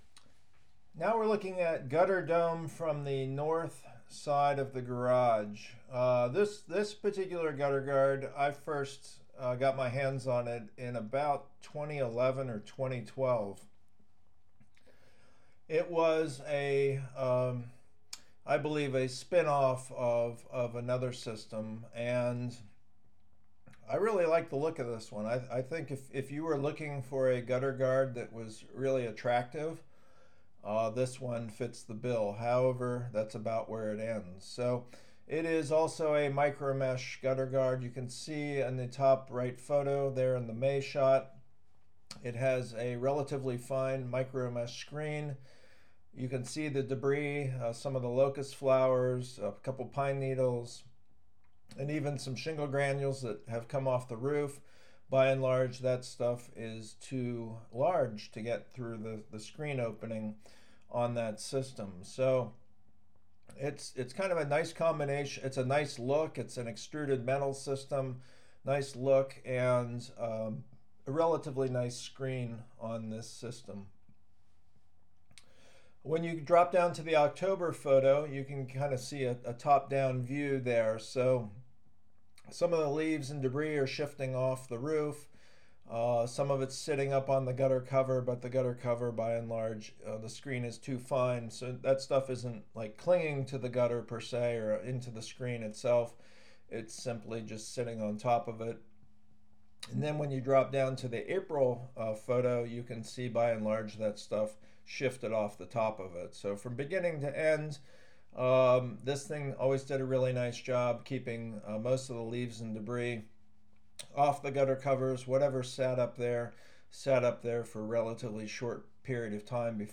Commentator